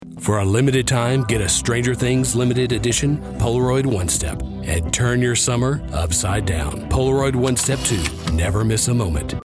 North American General, North American Southern
Commercial
He operates from a professional home studio equipped with an MKH416 microphone and Logic Pro, ensuring broadcast-quality audio with fast turnaround times.